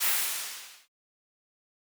steam hisses - Marker #1.wav